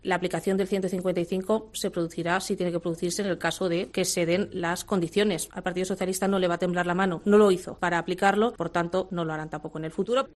Así lo ha señalado la portavoz del Comité Electoral socialista, Esther Peña, en una rueda de prensa en la que ha avanzado que "en las próximas horas" se conocerán las medidas "valientes" con las que el Gobierno responde a las actuaciones de la Generalitat respecto a los mossos, los CDR y las declaraciones del presidente Torra llamando a la "vía eslovaca".